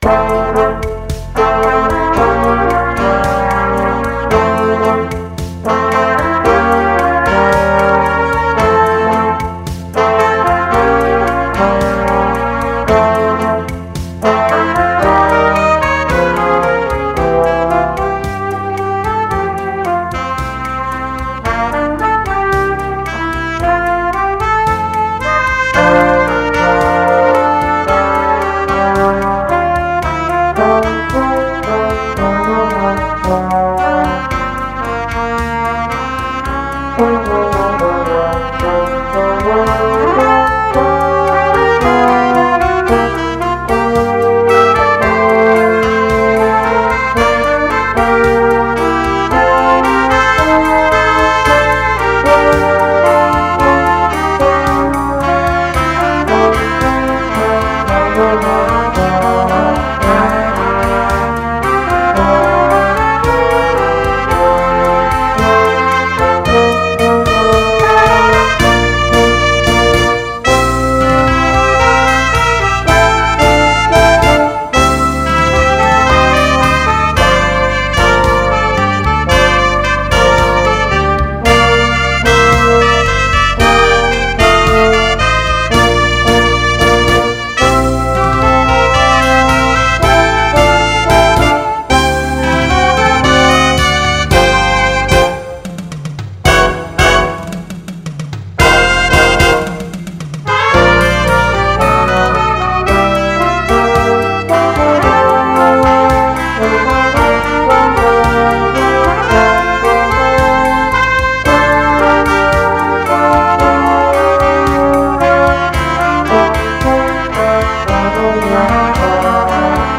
Genre: Jazz Ensemble